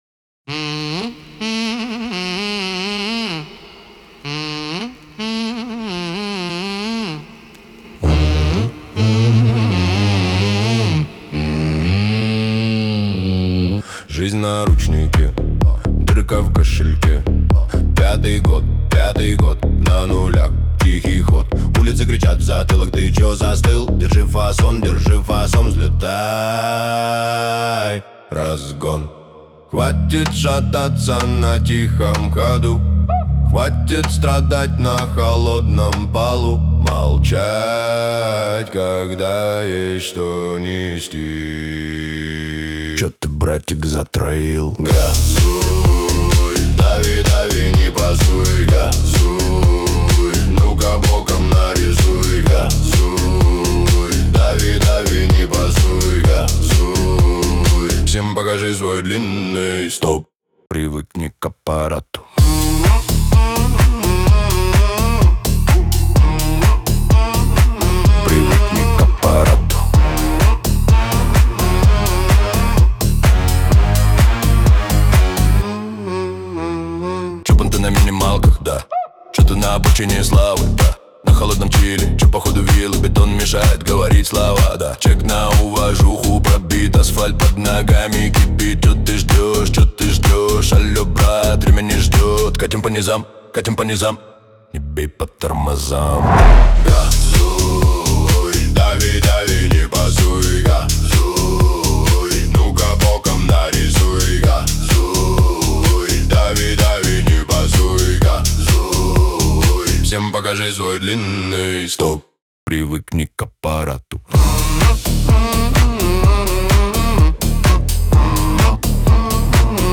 Клубные хиты